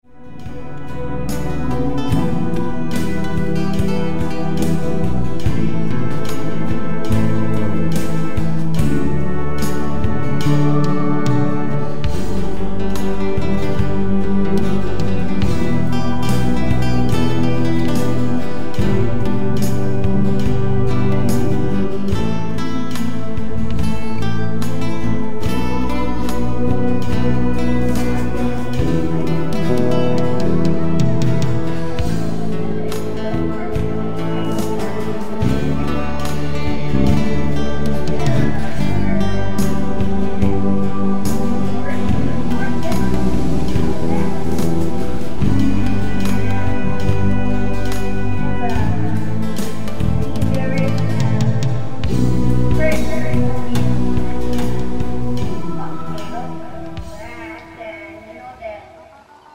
Sie sind zum träumen und entspannen gedacht.